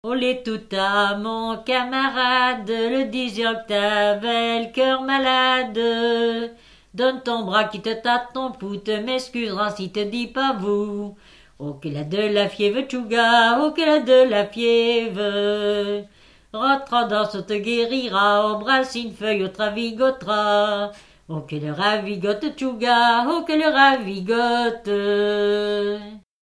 Danse ronde
Catégorie Pièce musicale inédite